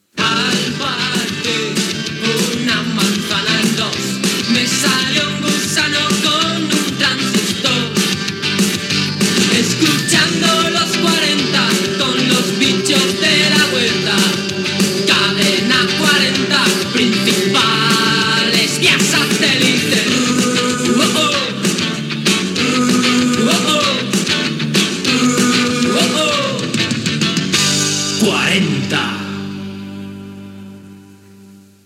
Indicatiu de la cadena